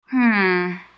hmm2.wav